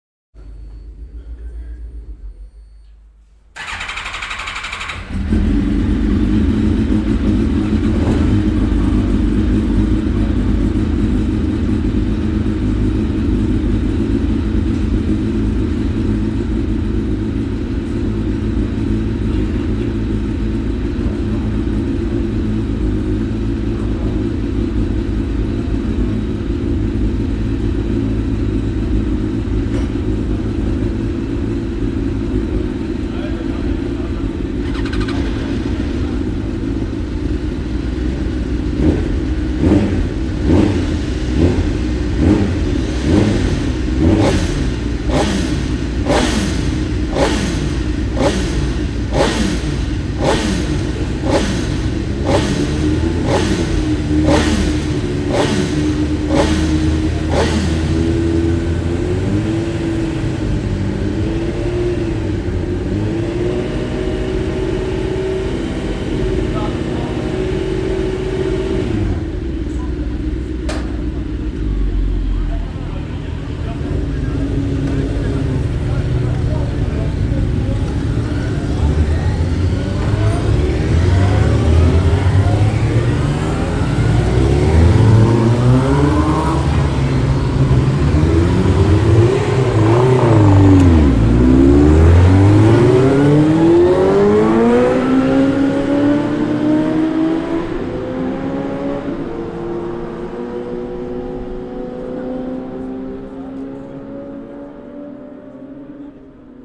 oreilles avec de délicieux chants de moteurs et d'échappements qui nous font si souvent